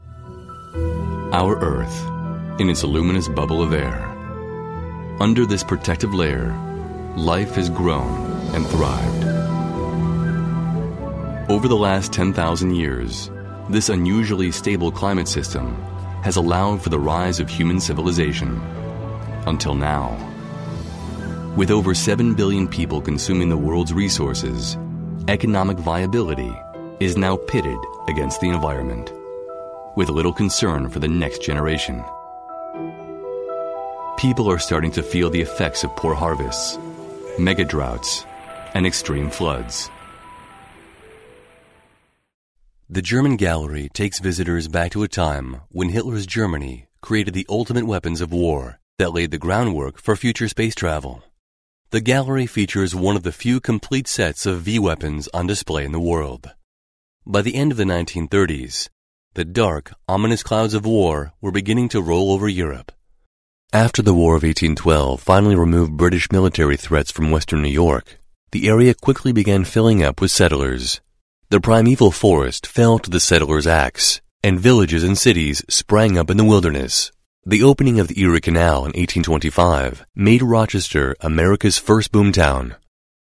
I have my own professional recording studio which includes: -Zephyr Telos ISDN Codec -Neumann U87 Condenser Microphone -Neumann TLM 103 Condenser Microphone -Fireface 400 Audio Interface -RØDE NT4 -X/Y Stereo Condenser Microphone -API 7600 Pre-Amp -SPL Channel One -Transistor/Tube pre-amplifier -Digidesign Mbox2 -PreSonus Monitor Station Here are a few of my clients: Adidas Solomon, AG Mercedes Benz, Avaya, 3U Telecom AG, Suzuki Automobile, DWS Investments, Sony Ericsson, Fiat Automobile, Hugo Boss, Nokia, Goethe Institute, CeBIT- Deutsche Messe AG, SVT Fire, Studio Hamburg, Deutsche Telekom, Russian Standard Vodka Volkswagen Automobile, Sixteen Tons Entertainment Targem Games Freeze Interactive Freeze Interactive, Nissan, Haas, Merck Pharmaceuticals Skoda Ceidot Games, British American Tobacco Banyan Tree Private Collection Haweka Nintendo BMW Broadcast,Qporter, Realtech Corporation ThyssenKrupp AG Interone Worldwide Time Traveler Show,NDR (Nord Deutsche Rundfunk) Eurosport TV Gillette Fusion Braun Procter & Gamble RTN2 Osram Holland Banking, SOS Kinderdorf, Lavendelfilm, Nivea, Puma, Bayer, MINI, König & Cie., Axe, Jamba, Swiss RE, Blackberry, Bewag, Bilz, Lufthansa, Ethicon, Carlsberg, Coca-Cola
Sprecher amerikanisch englisch.
Stimmfarbe: Tief, sexy, erfahren.
mid-atlantic
Sprechprobe: Sonstiges (Muttersprache):